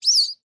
assets / minecraft / sounds / mob / dolphin / idle5.ogg